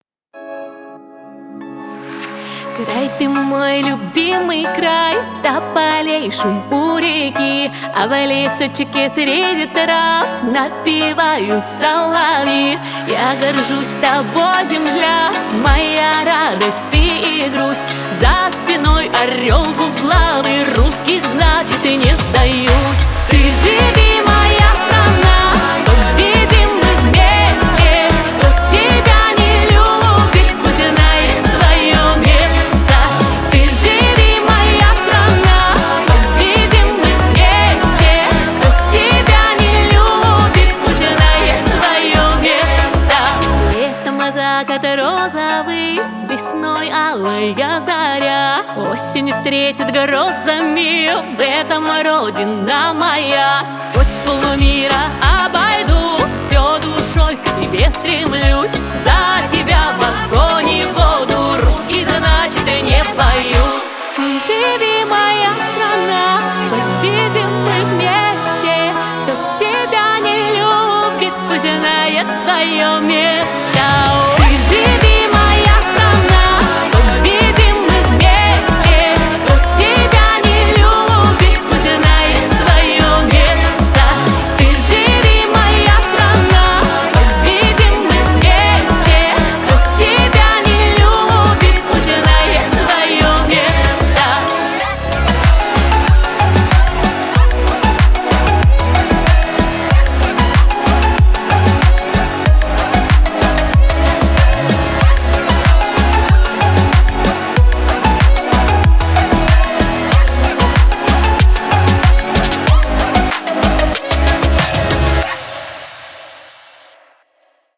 • Категория: Детские песни
народный мотив